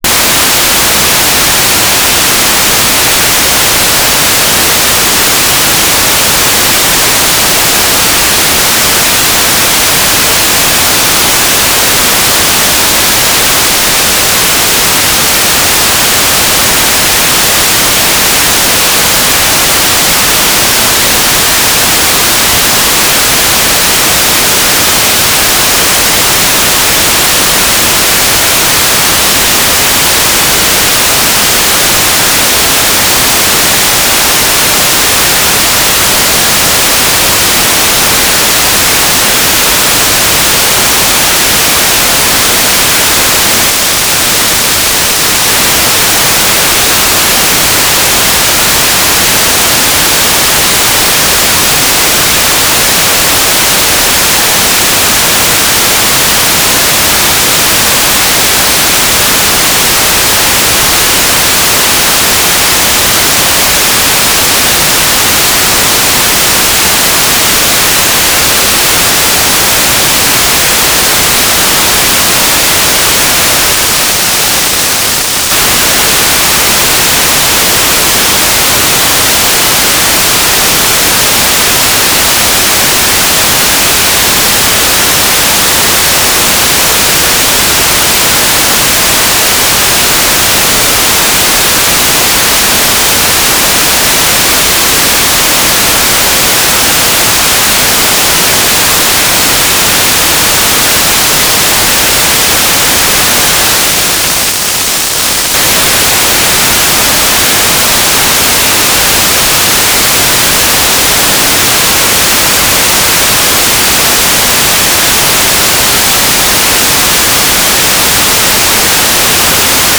"transmitter_description": "Mode U - GMSK2k4 - USP",
"transmitter_mode": "GMSK USP",